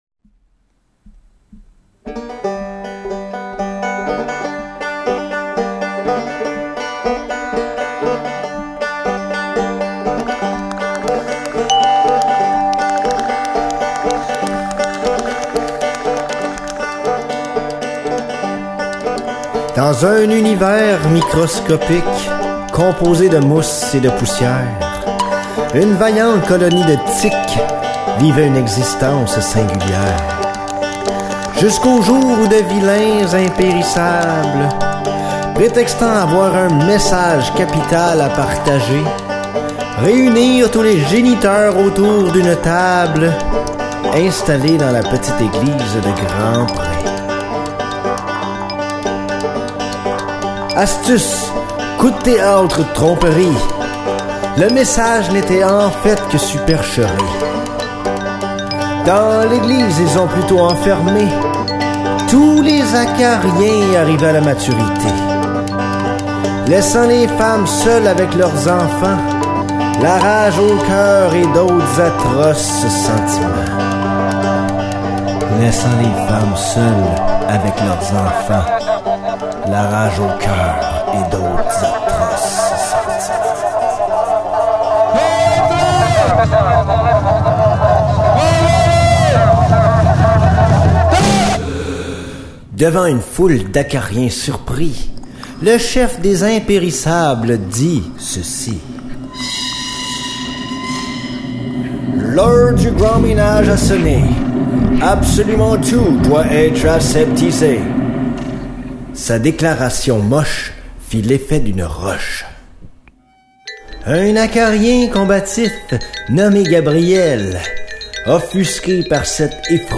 .: Lectures :.